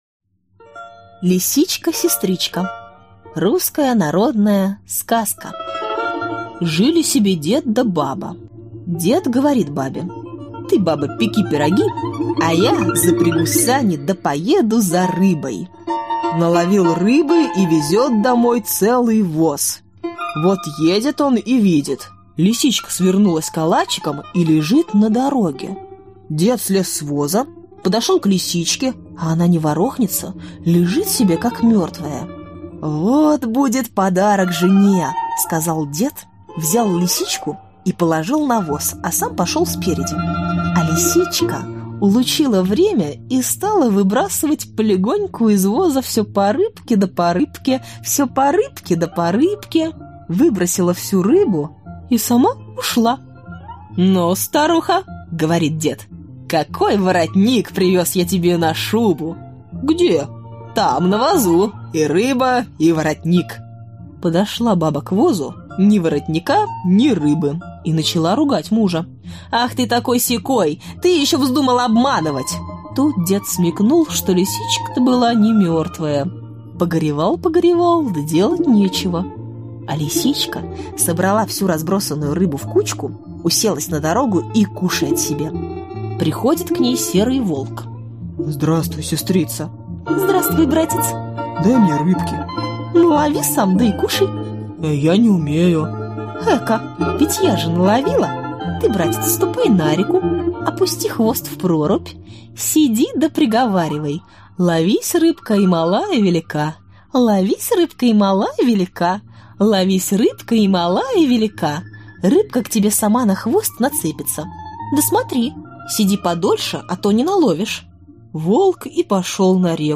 Аудиокнига Сказки-невелички о Лисичке
Сказки записаны в исполнении актеров театра и кино. В сборник включены лучшие образцы сказочного фольклора. 1.